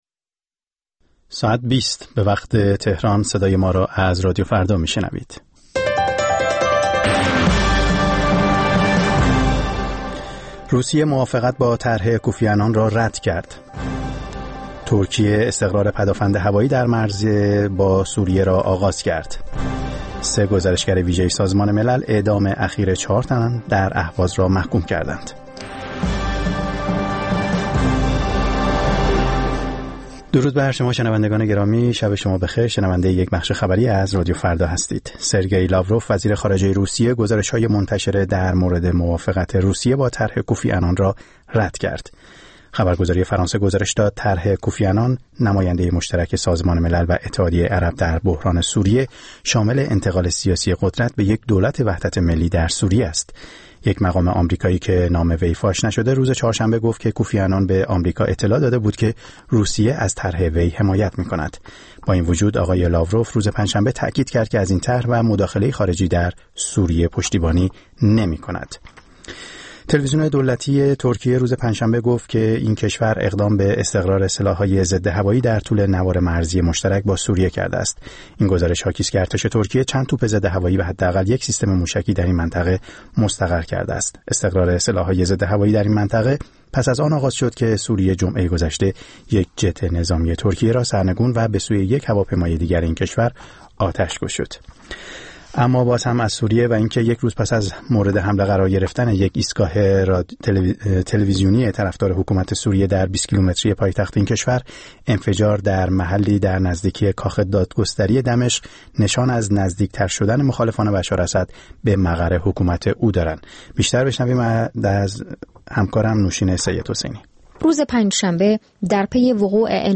برنامه زنده موسیقی